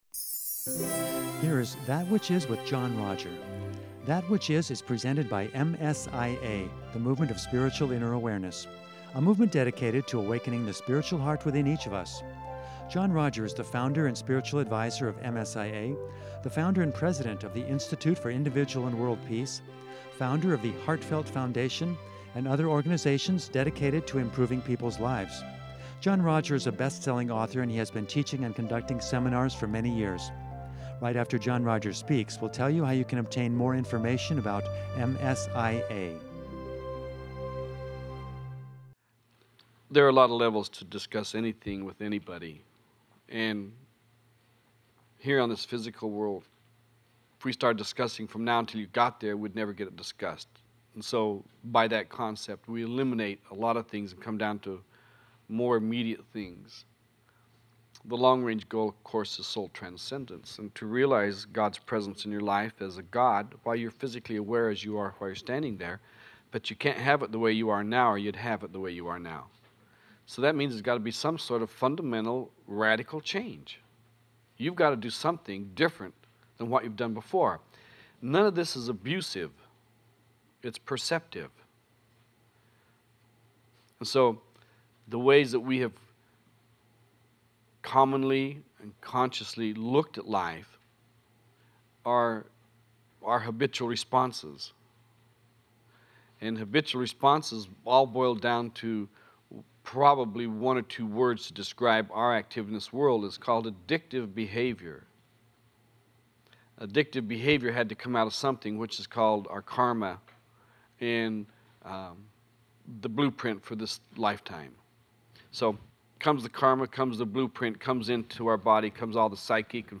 The title of this seminar leads us to the heart of our lives.